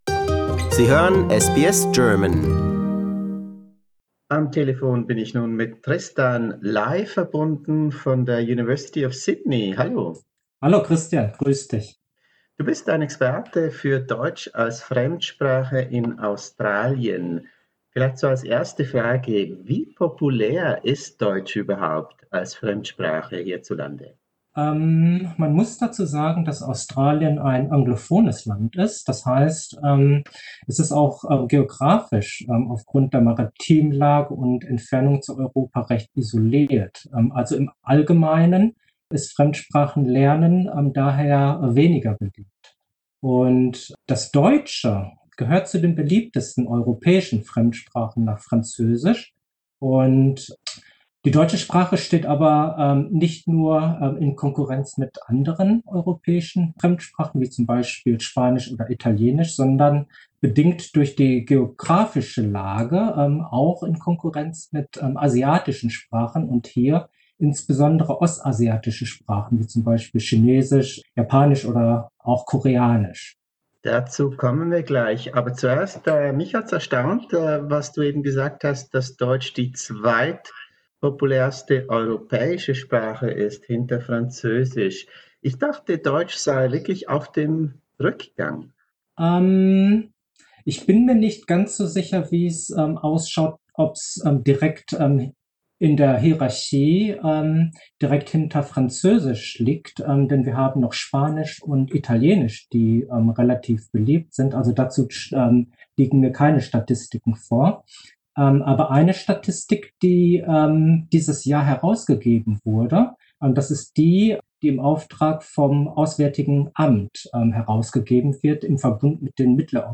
Stimmt die Beobachtung, dass unsere deutsche Muttersprache an den australischen Schulen und Universitäten zunehmend von asiatischen Sprachen verdrängt wird? Was heisst dies für die Zukunft von Deutsch als Fremdsprache in Australien? Dazu ein Gespräch